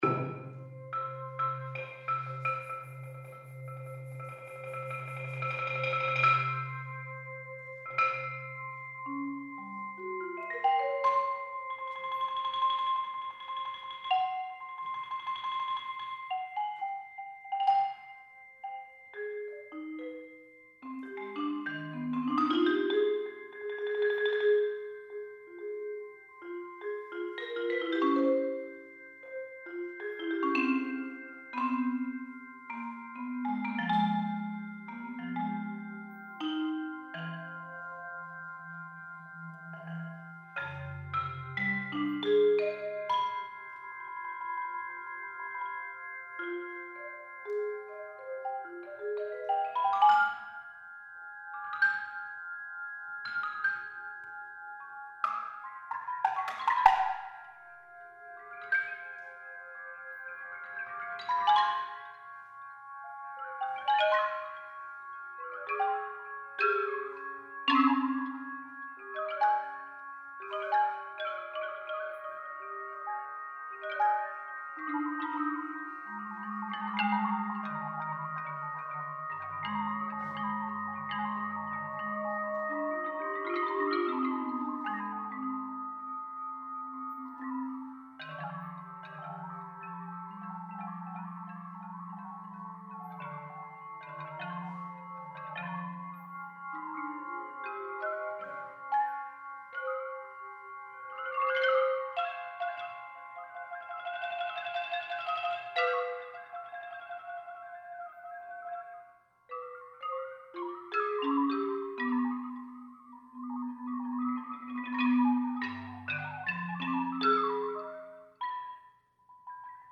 Computer Music
for cello and computer.